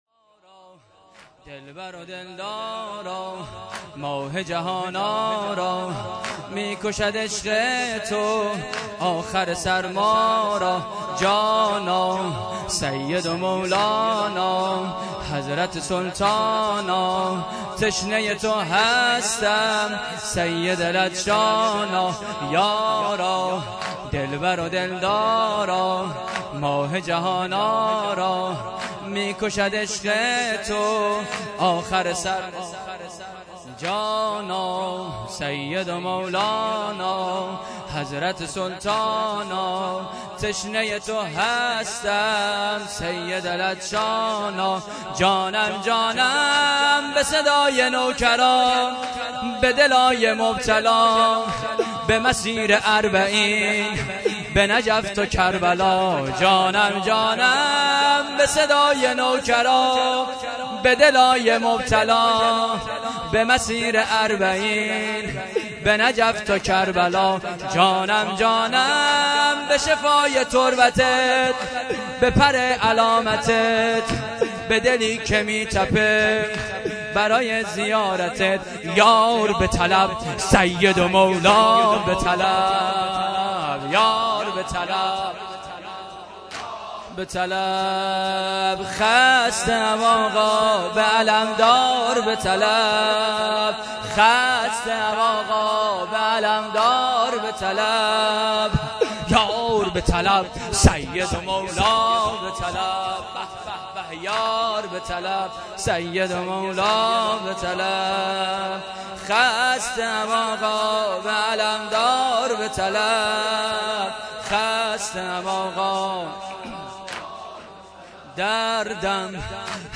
واحد تند
شب‌ پنجم محرم الحرام ۹۷ هیات انصار المهدی (عج)